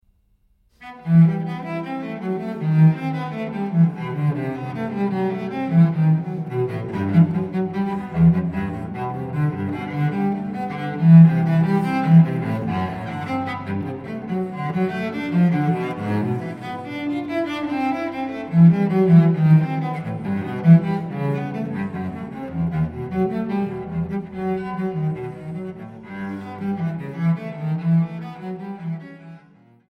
Transkiptionen für Violoncello